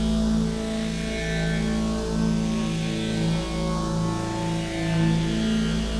Index of /musicradar/dystopian-drone-samples/Non Tempo Loops
DD_LoopDrone4-A.wav